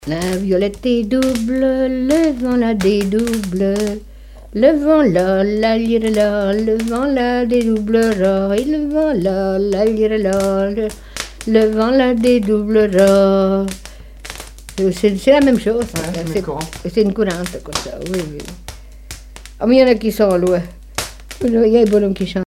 Divertissements d'adultes - Couplets à danser
branle : courante, maraîchine
Pièce musicale inédite